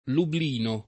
vai all'elenco alfabetico delle voci ingrandisci il carattere 100% rimpicciolisci il carattere stampa invia tramite posta elettronica codividi su Facebook Lublino [ lubl & no ] top. (Pol.) — pol. Lublin [ l 2 blin ]